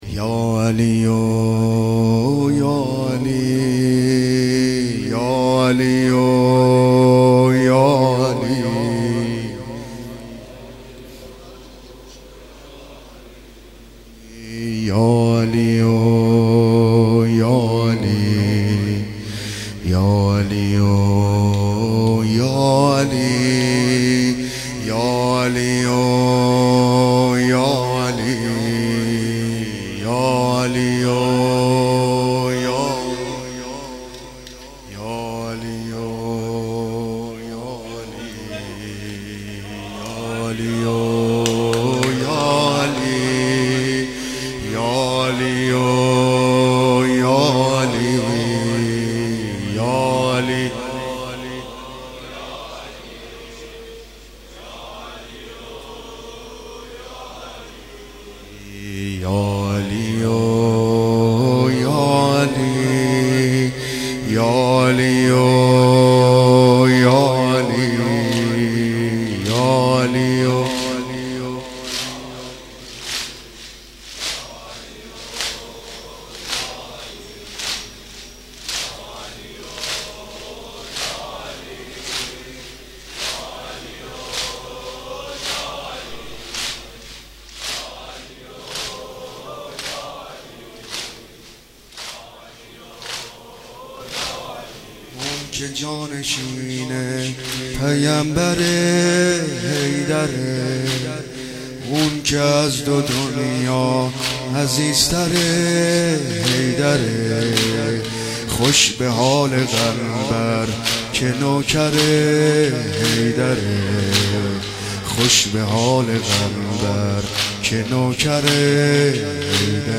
سینه زنی زمینه
سینه زنی شور ، واحد
سینه زنی واحد ، تک ، شور